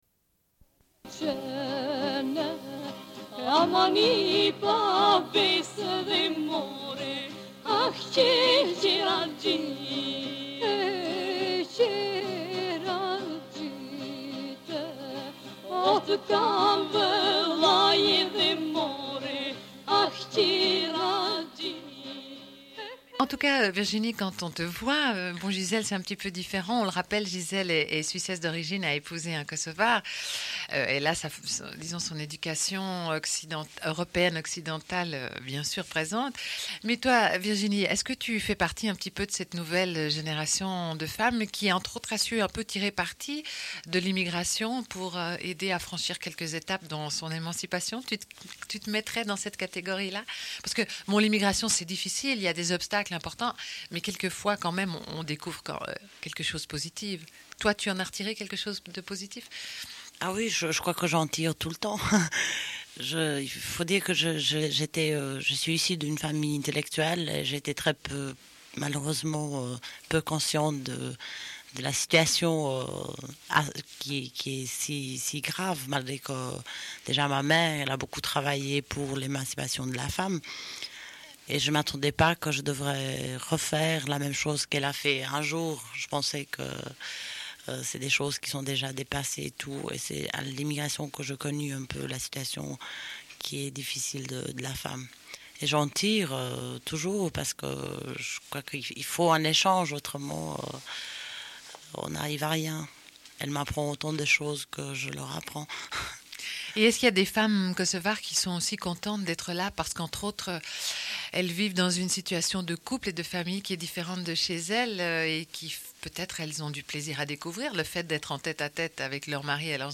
Radio Enregistrement sonore